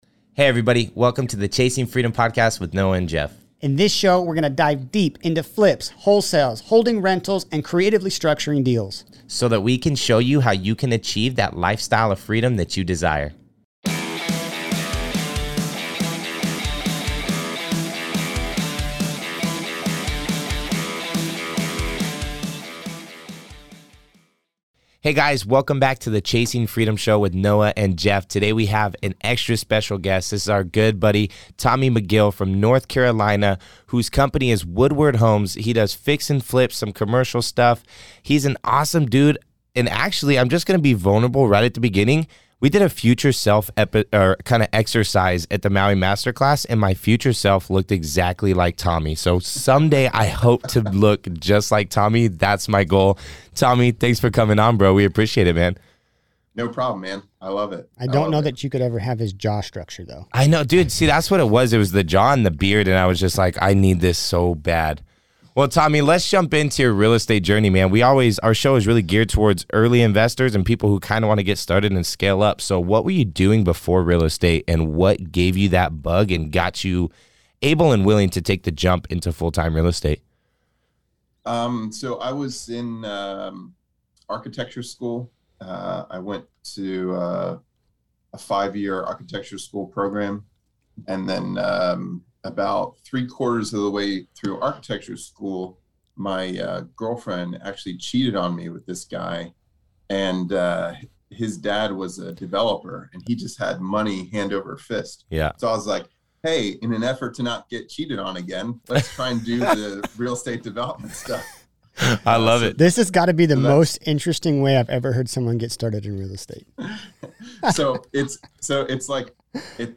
Super fun interview with tons of great knowledge!